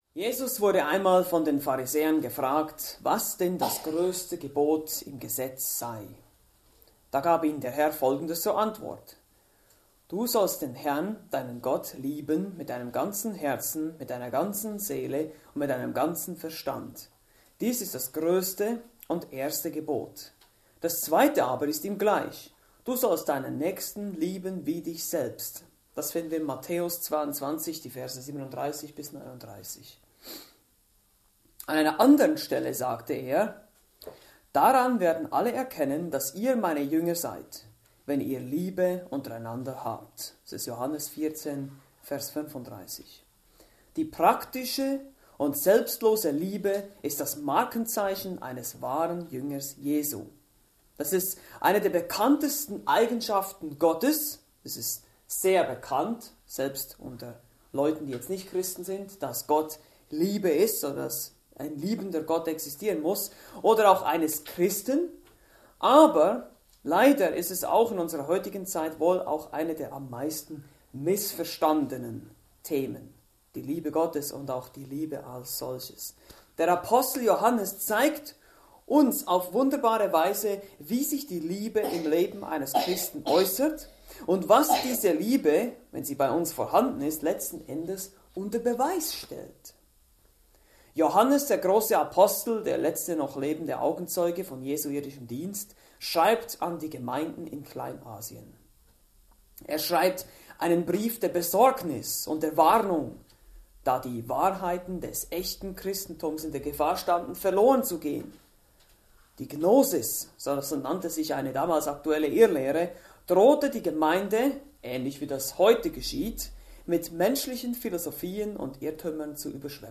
Predigten Übersicht nach Serien - Bibelgemeinde Berlin